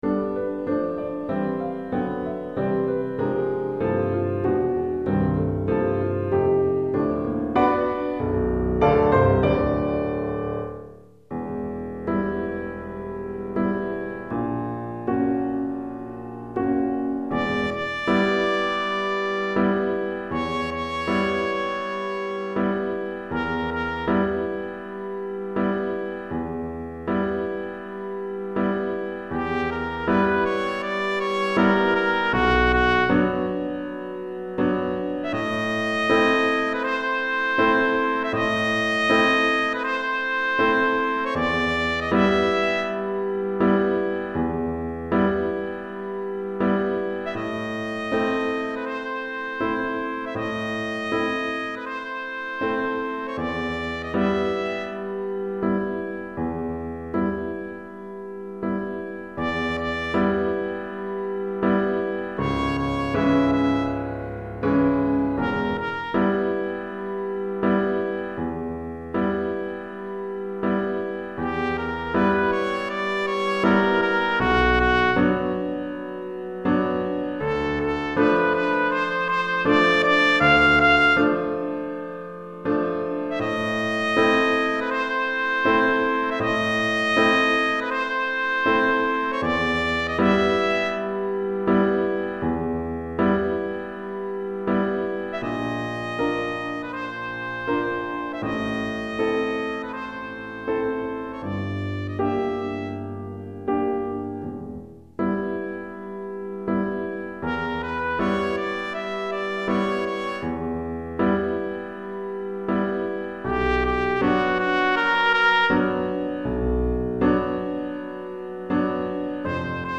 Trompette et Piano